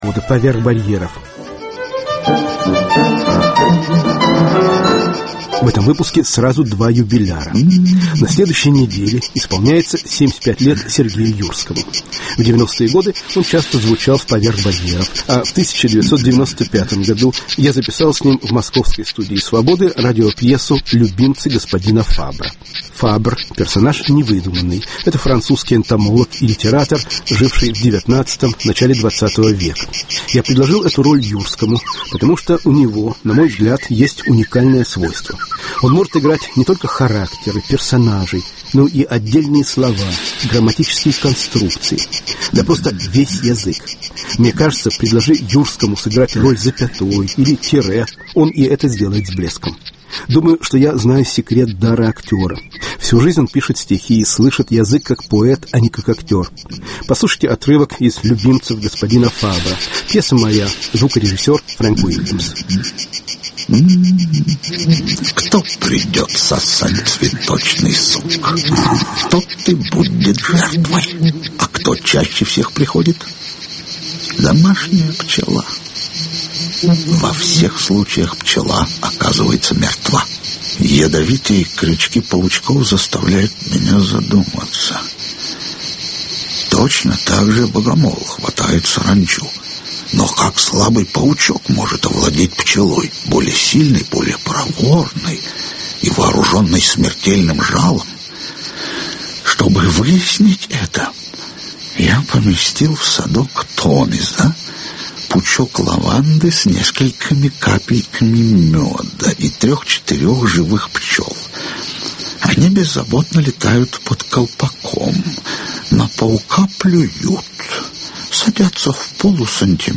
отрывки из радиоспектакля "Любимцы господина Фабра